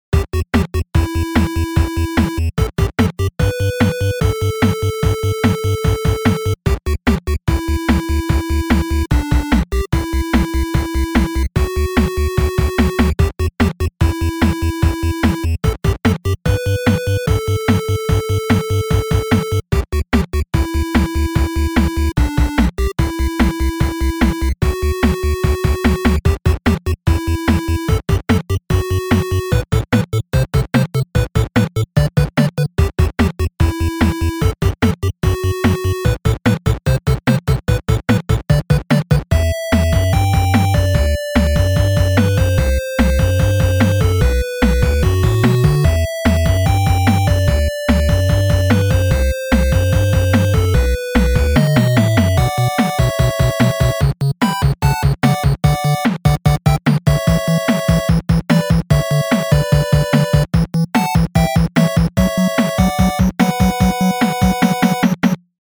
Its a fighting song inspired by the good old GB.